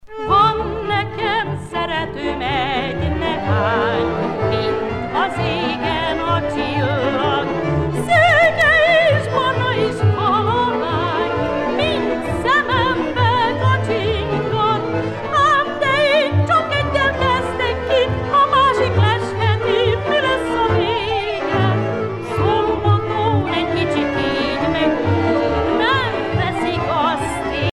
danse : csárdás (Hongrie)